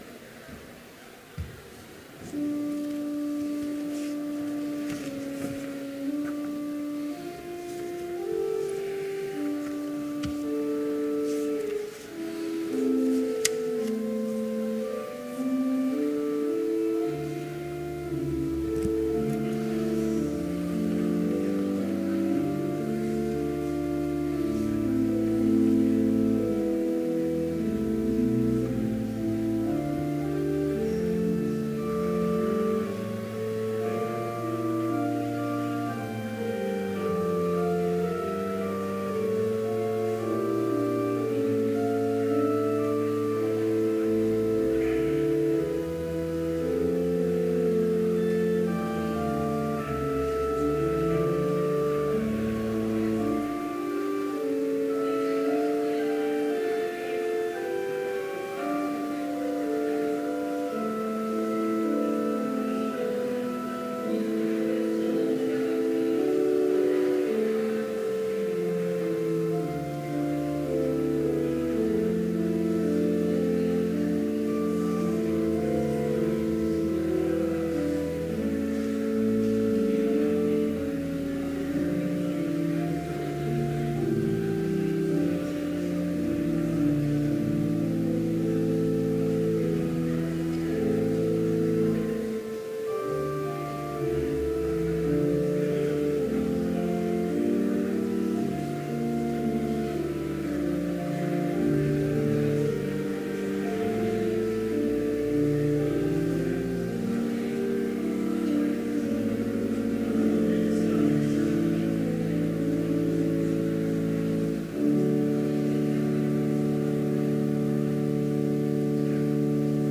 Complete service audio for Chapel - April 20, 2017